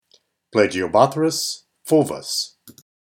Pronunciation/Pronunciación:
Pla-gi-o-bó-thrys fúl-vus